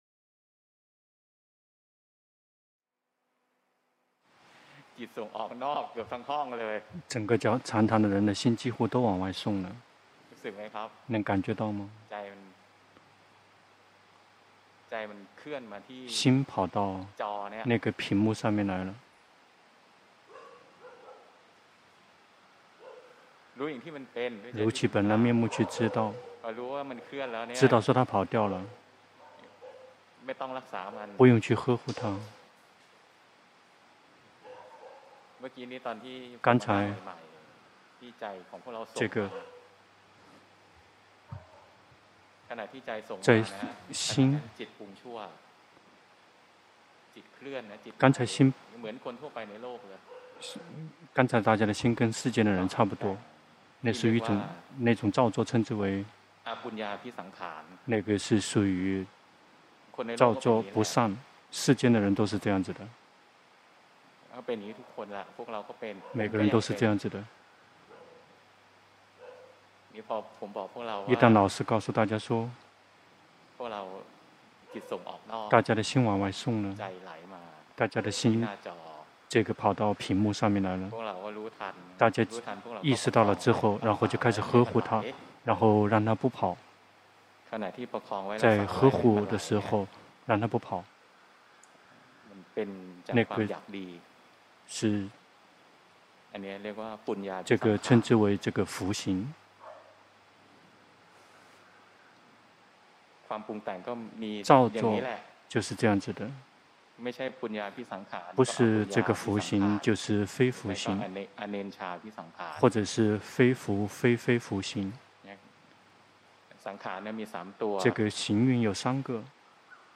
長篇法談｜突破修行的難點